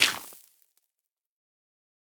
brushing_gravel_complete3.ogg